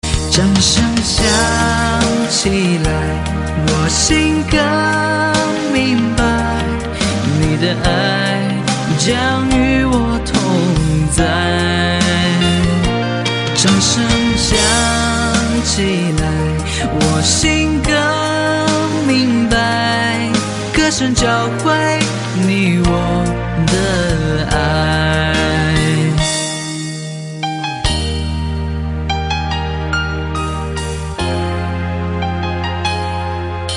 M4R铃声, MP3铃声, 华语歌曲 64 首发日期：2018-05-15 14:20 星期二